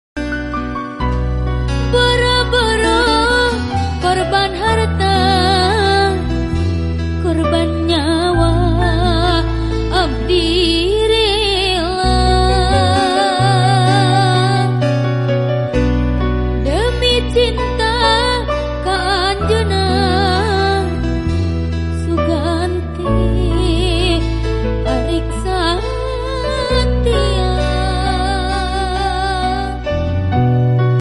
ACOUSTIC COVER